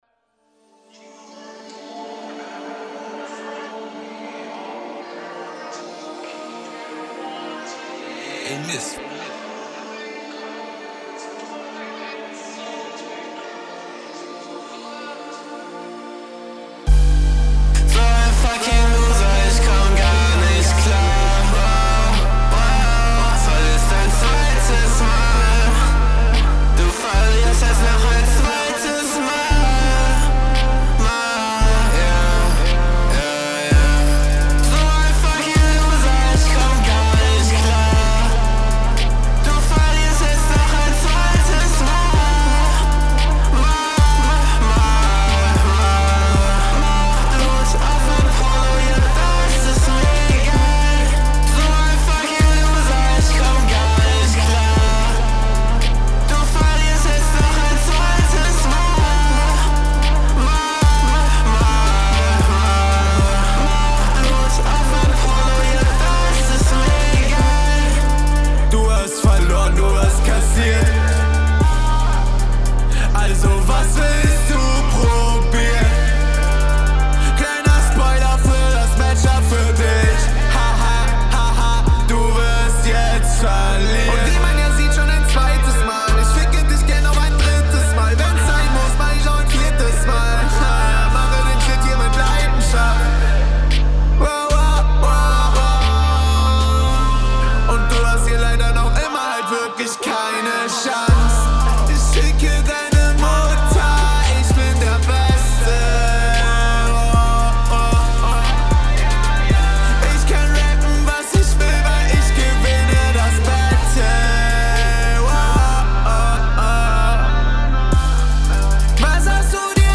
Uff. Autotune xD.